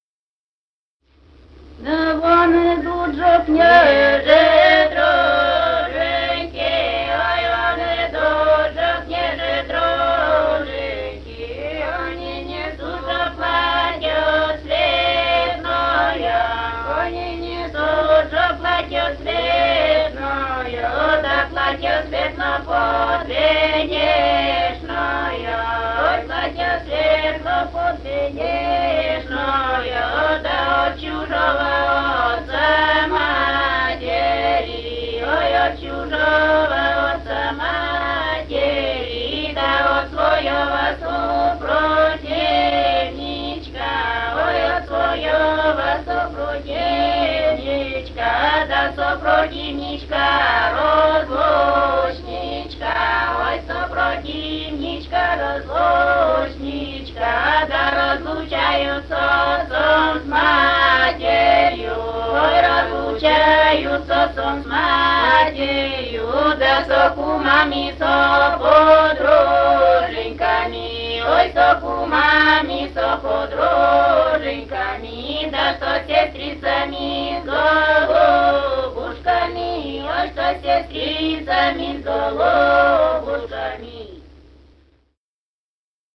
Этномузыкологические исследования и полевые материалы
Пермский край, д. Монастырка Осинского района, 1968 г. И1075-02